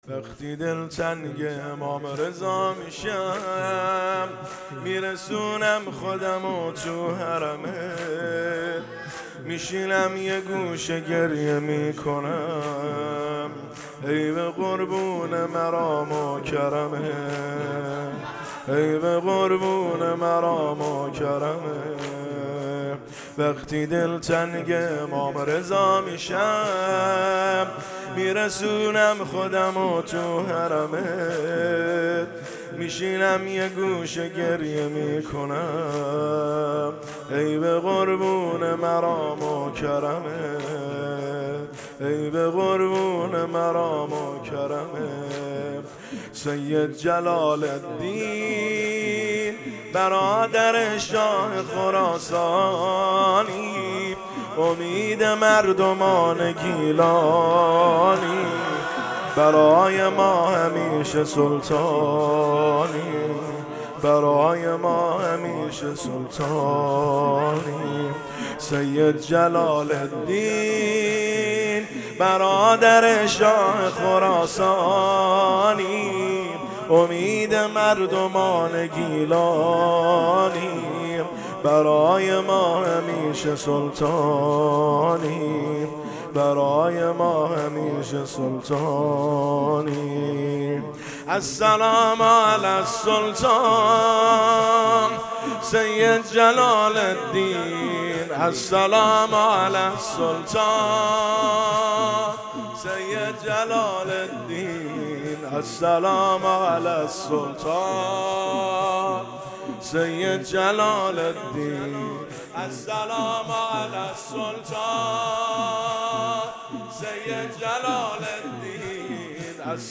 هیئت فاطمة الزهرا (س) آستانه اشرفیه